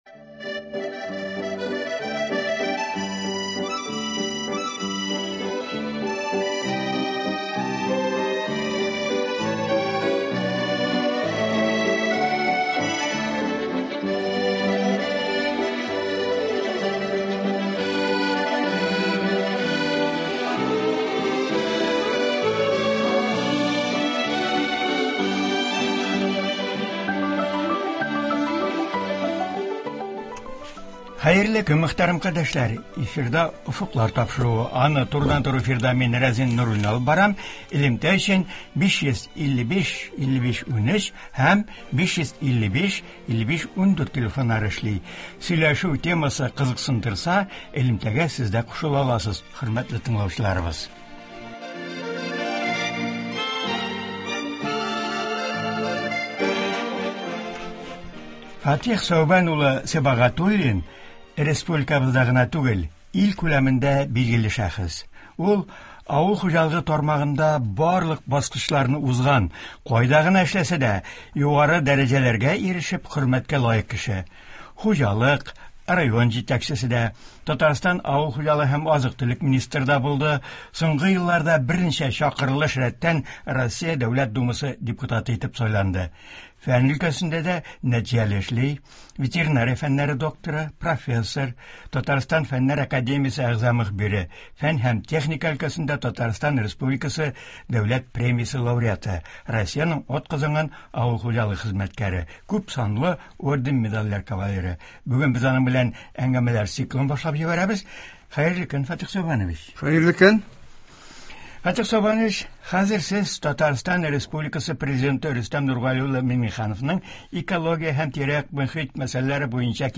Турыдан-туры элемтә тапшыруында Россия Дәүләт Думасы депутаты вакытында мохитне саклау мәсьәләләренә аеруча зур өлеш керткән шәхес, ветеринария фәннәре докторы, профессор Фатыйх Сәүбән улы Сибагатуллин катнашачак, мохитебезне саклаудагы мөһим мәсьәләләргә тукталачак, тыңлаучылар сорауларына җавап бирәчәк.